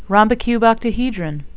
(rom-bi-cube-oct-a-he-dron)